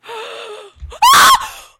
害怕，然后尖叫
描述：警告：大声 有些东西吓坏了我，然后跳了过来
Tag: 尖叫 吓了一跳 害怕